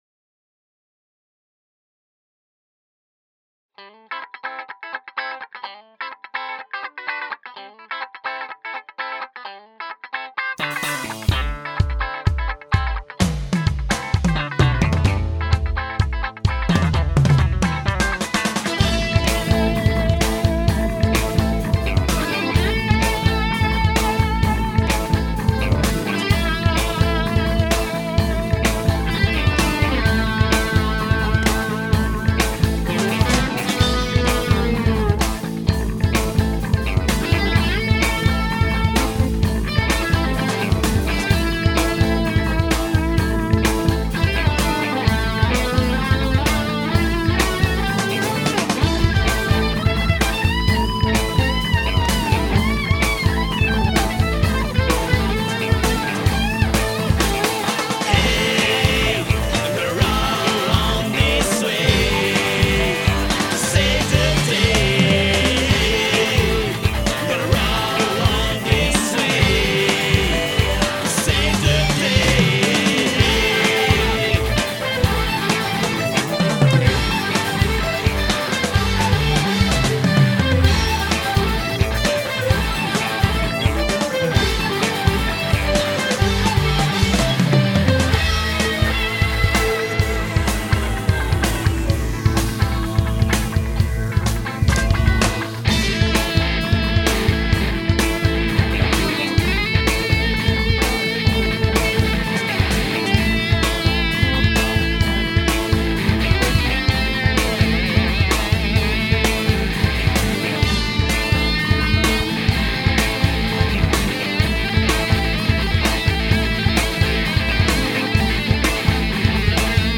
Genere: Funk Rock
basso
tastiera
chitarra/voce
batteria
Poco a poco però, dopo le prime prove, il genere su cui era basato il gruppo assume caratteristiche proprie innovative grazie alla presenza di musicisti di influenze varie, ma organiche, che mescolate assieme portano alla stesura dei primi 2 brani Smoke Signals e Roll On This Way, entrambi con una forte base ritmica funky.